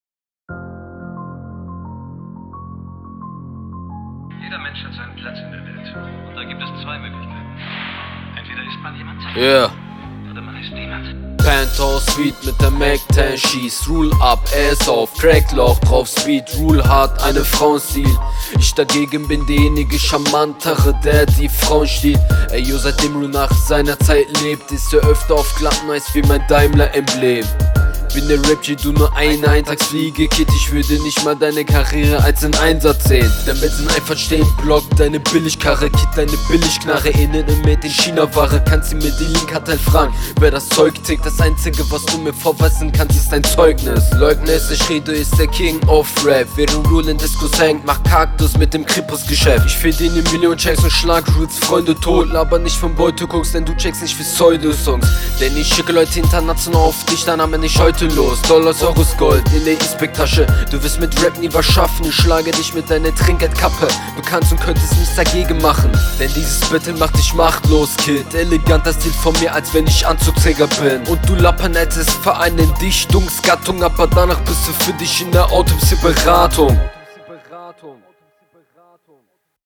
flow nich mehr als standart